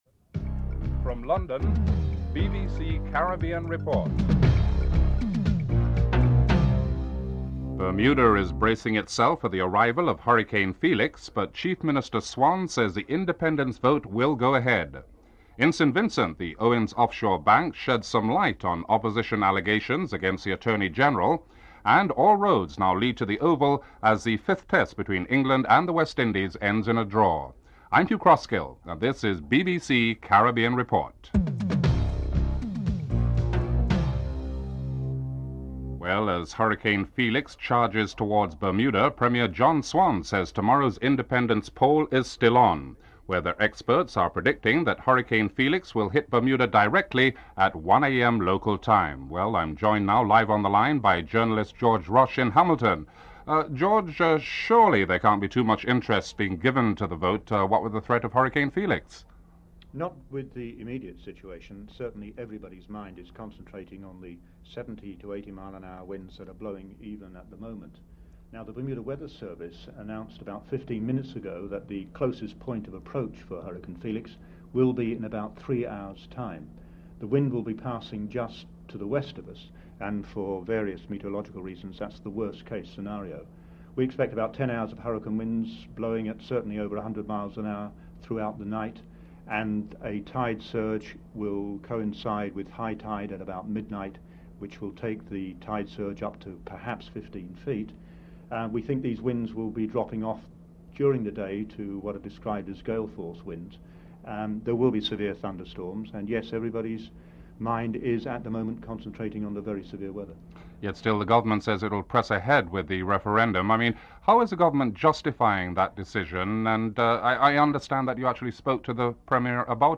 Caribbean Report interviewed some residents to find out how they dealt with the tremors.